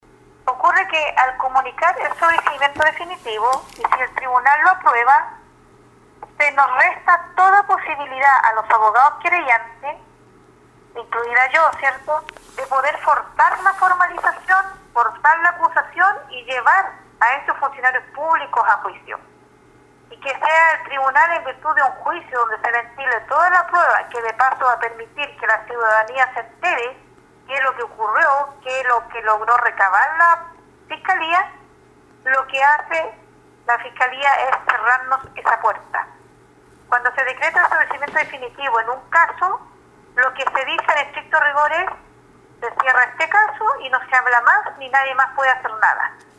En entrevista con radio Estrella del Mar dijo que esta situación es grave dado que las acciones del ministerio público buscan dejar sin responsabilidades civiles ni políticas el alto daño ambiental y económico que generó el desastre ambiental en la región desde 2016.